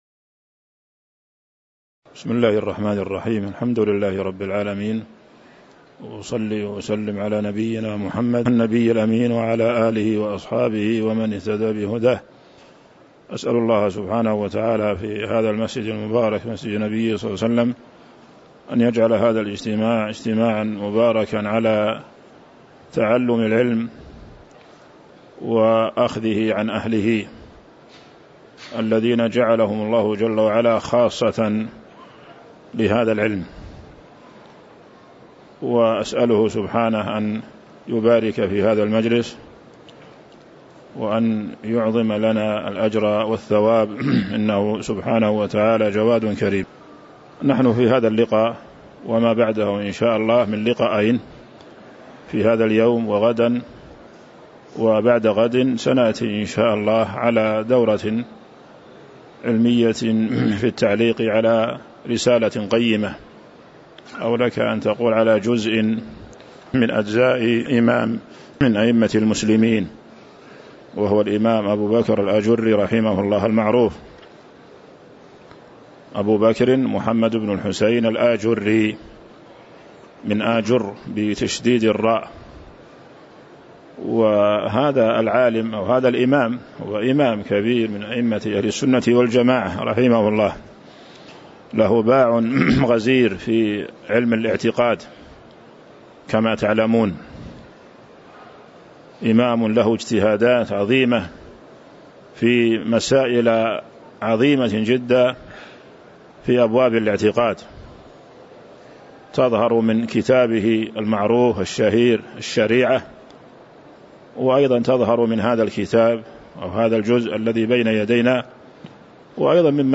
تاريخ النشر ١٦ ربيع الثاني ١٤٤٥ هـ المكان: المسجد النبوي الشيخ